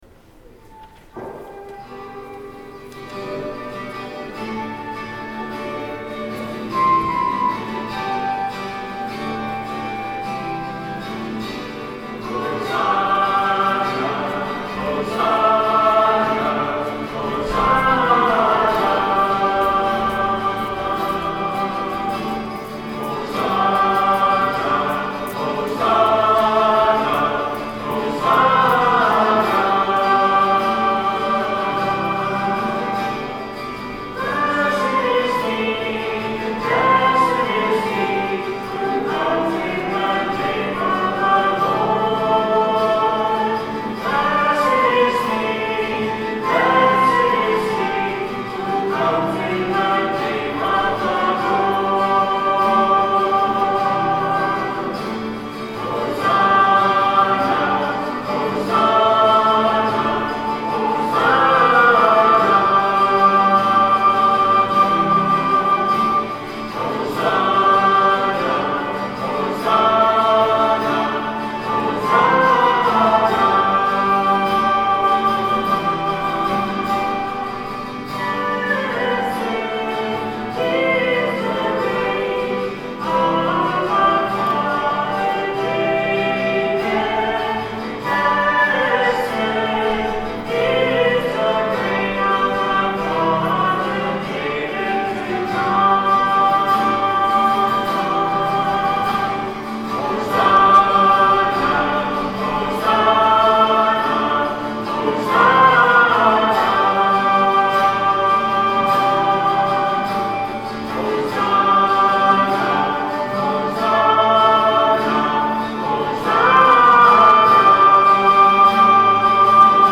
03/28/10 10:30 Mass Recording of Music
Music from the 10:30 Mass on Sunday, March 28, 2010 Note that all spoken parts of the Mass have been removed from this sequence, as well as the sung Mass parts.